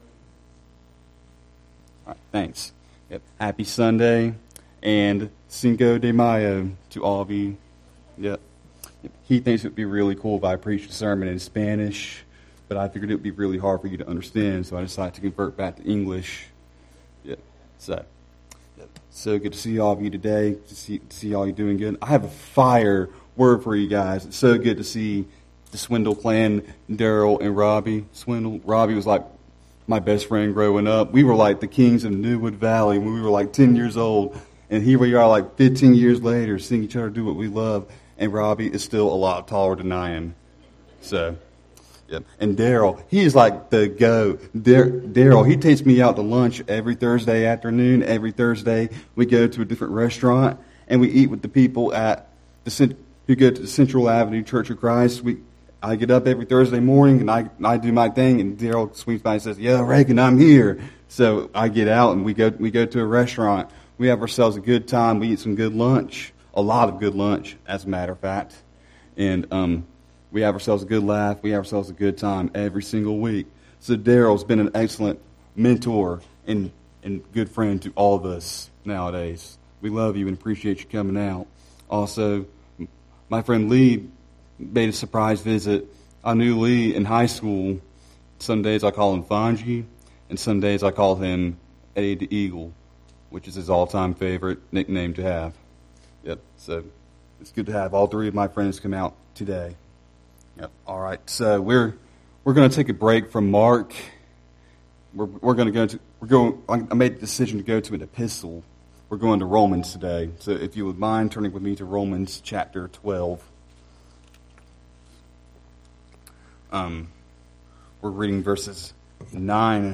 Sermon Archive
A Baptist Church serving the South Ga area including Valdosta, Morven, and Quitman